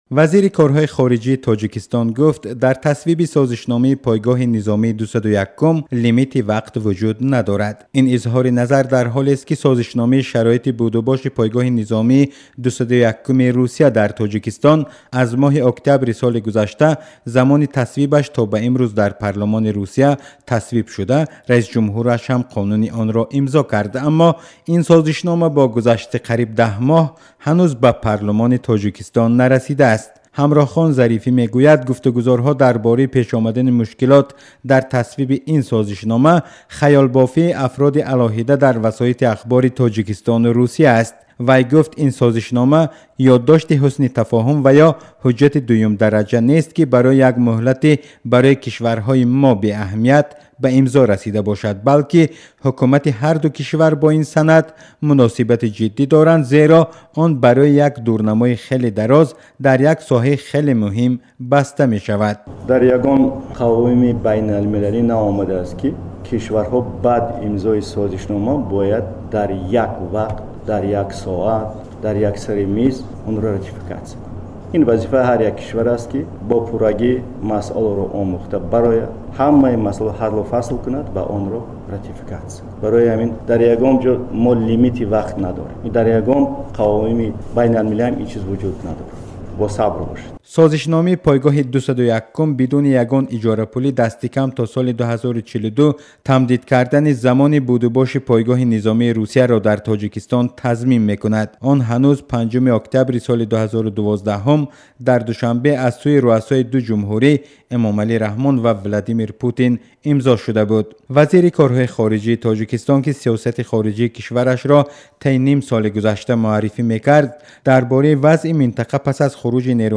Нишасти хабарии Ҳамроҳхон Зарифӣ